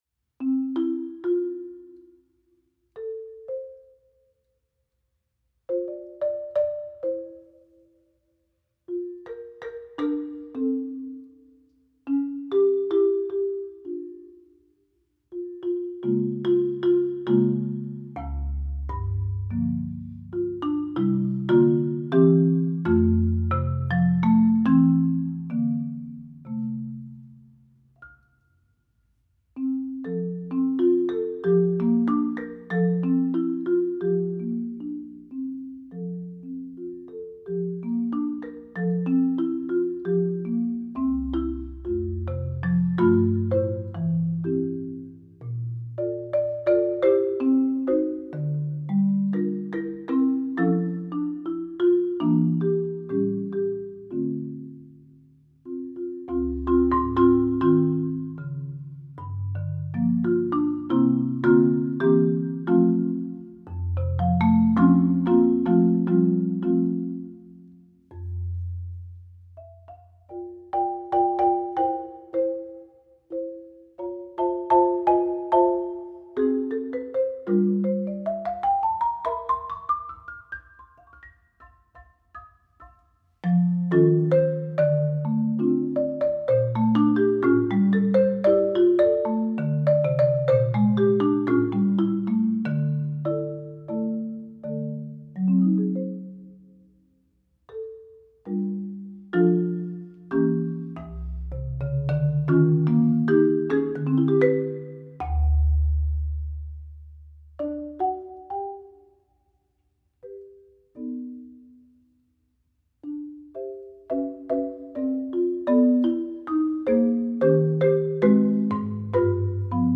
Voicing: Marimba Unaccompanied